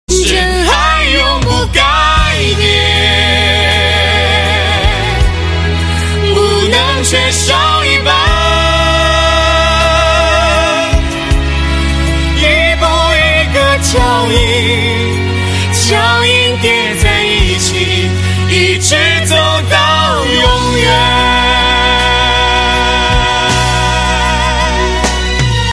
M4R铃声, MP3铃声, 华语歌曲 46 首发日期：2018-05-15 08:30 星期二